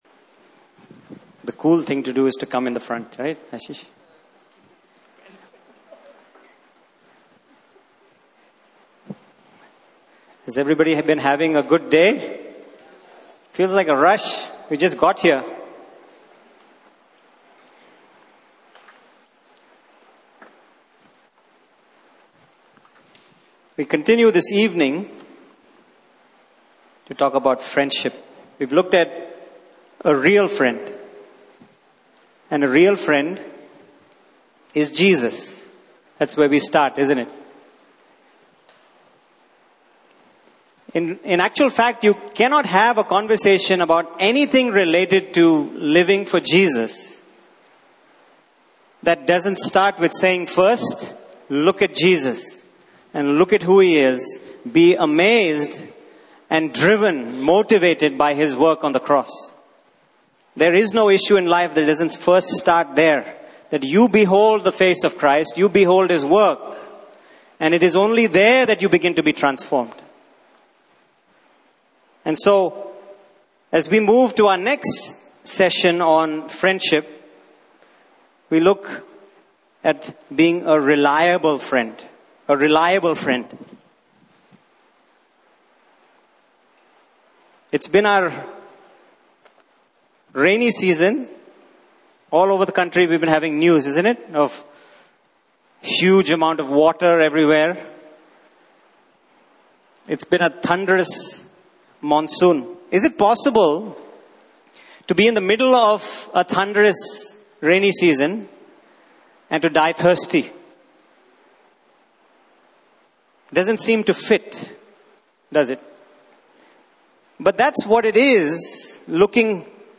Service Type: Main Session